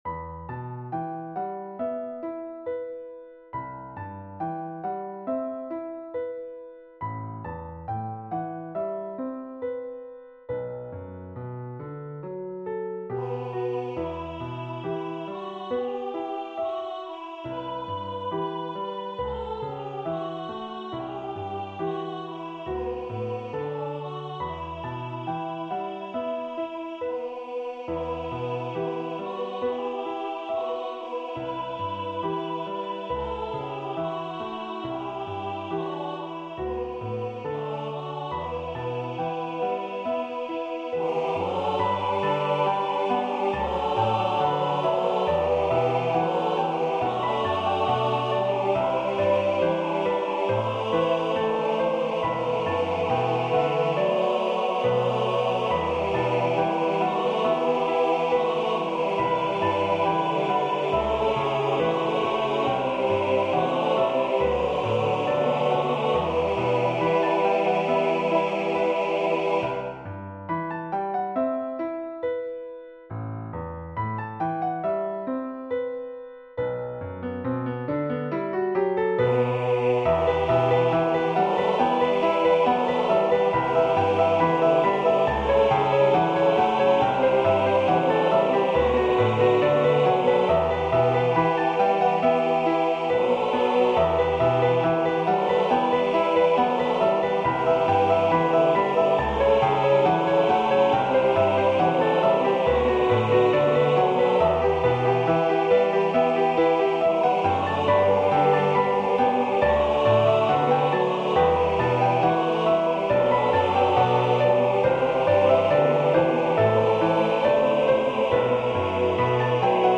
SATB with Piano Accompaniment.